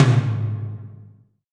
TOM TOM230SL.wav